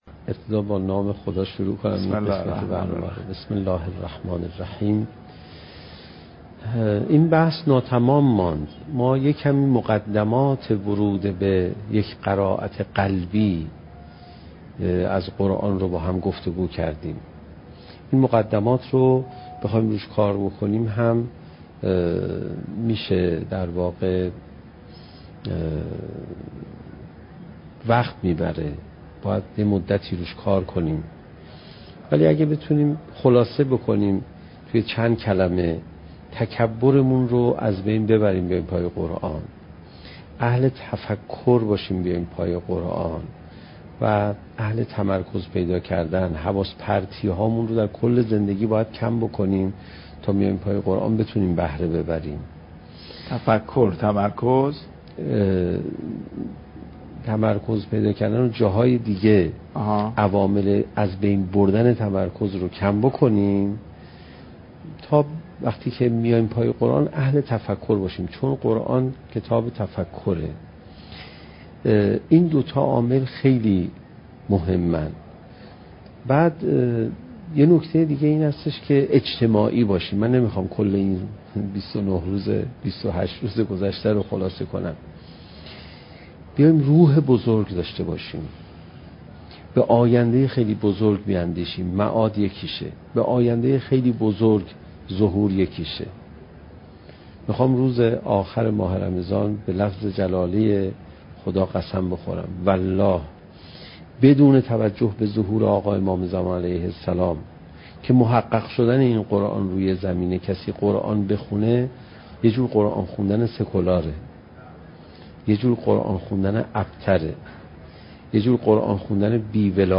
سخنرانی حجت الاسلام علیرضا پناهیان با موضوع "چگونه بهتر قرآن بخوانیم؟"؛ جلسه بیست و هفتم: "حال اشک پای قرآن"